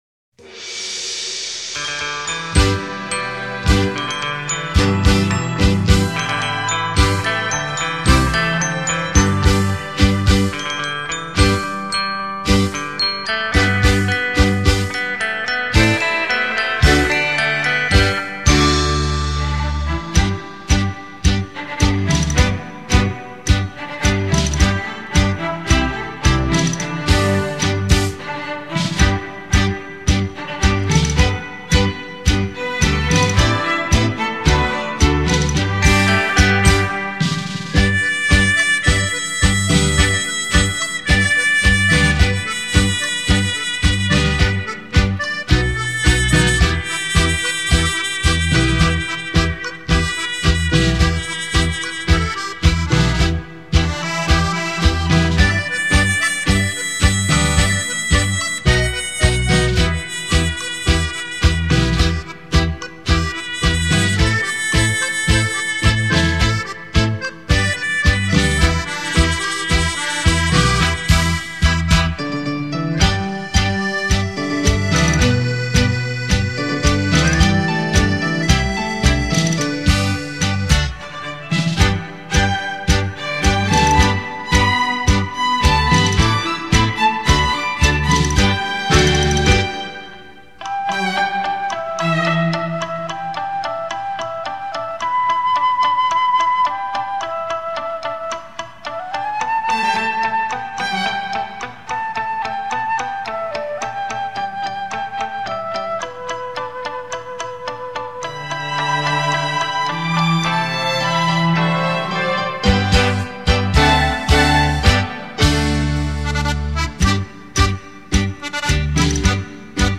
日本最知名的传唱名曲音乐，畅销日本演歌名曲演奏，翻唱成乡土传颂的熟悉老歌。
最佳舒压的休闲音乐， 精选各种乐器之经典畅销曲。